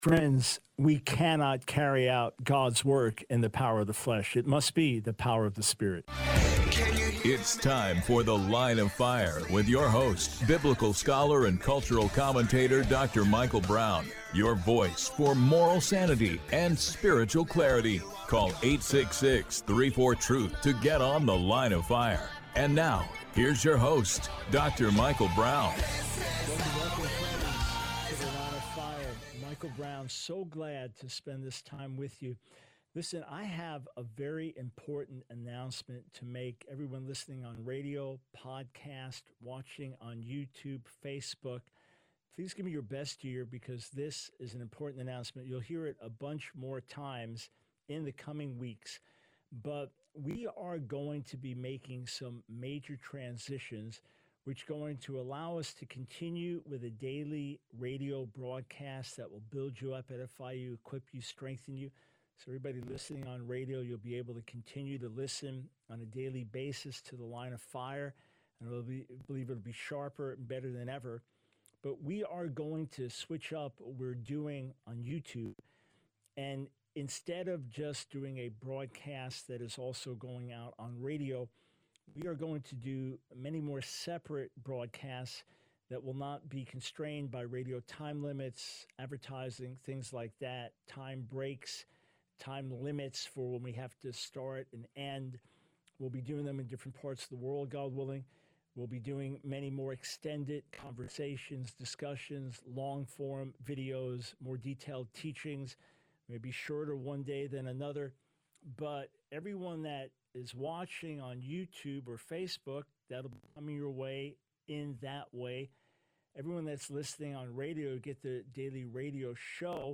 The Line of Fire Radio Broadcast for 09/23/24.